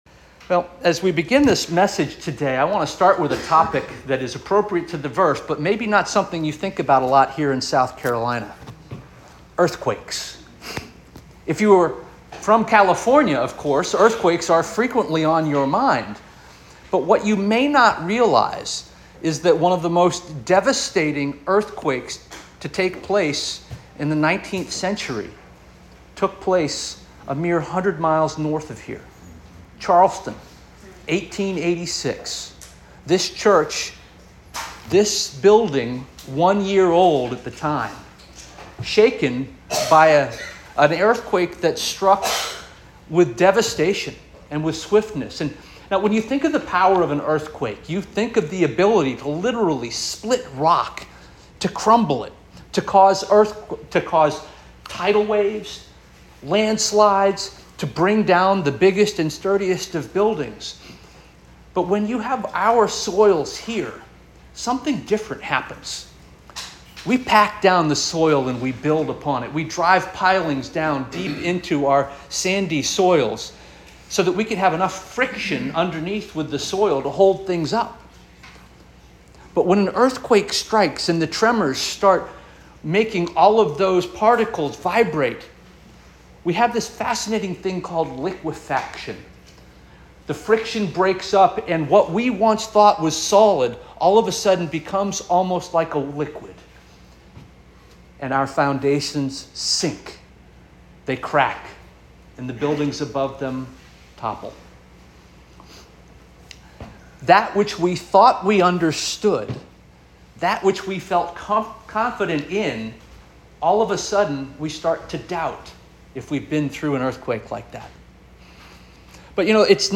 June 9 2024 Sermon